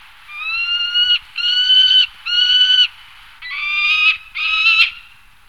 Faucon pèlerin
Falco peregrinus